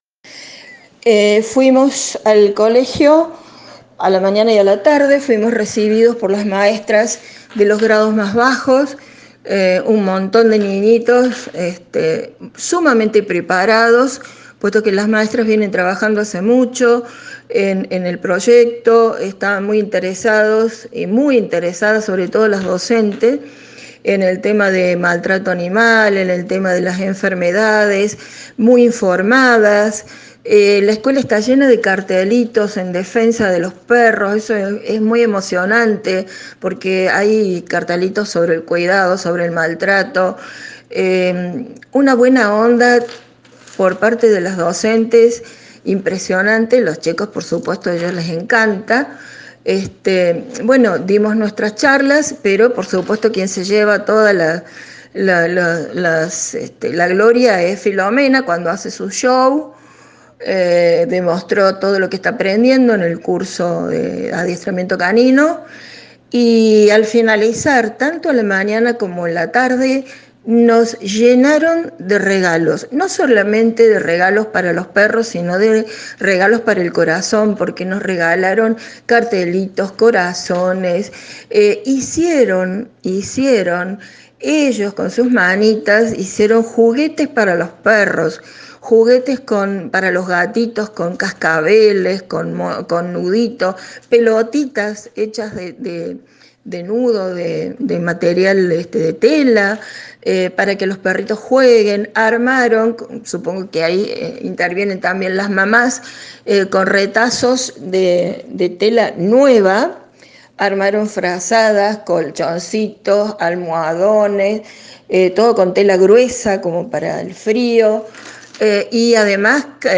una de las voluntarias de ADMA donde cuenta emocionada que sintieron desde la fundación cuando asistieron hoy a la escuela